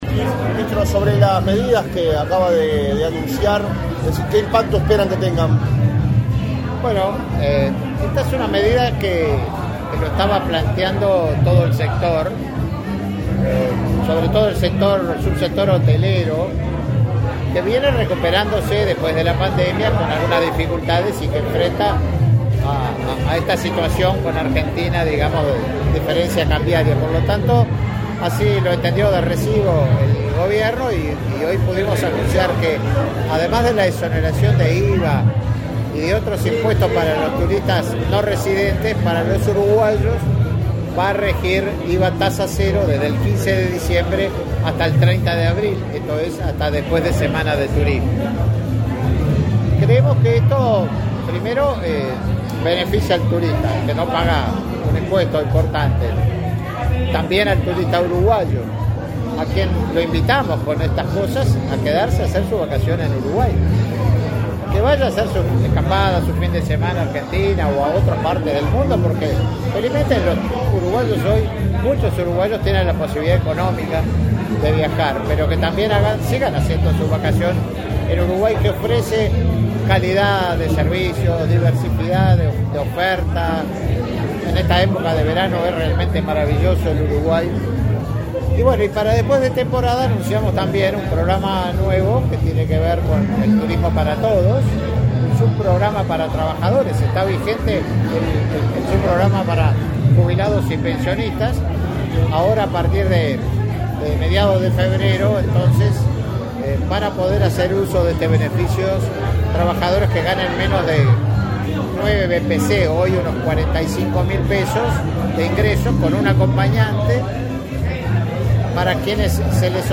Declaraciones a la prensa del ministro de Turismo, Tabaré Viera
Declaraciones a la prensa del ministro de Turismo, Tabaré Viera 18/11/2023 Compartir Facebook Twitter Copiar enlace WhatsApp LinkedIn El presidente de la República, Luis Lacalle Pou, participó, este 17 de noviembre, en el acto de lanzamiento de la temporada turística, en el balneario La Paloma, en el departamento de Rocha. Tras el evento, el ministro de Turismo, Tabaré Viera, realizó declaraciones a la prensa.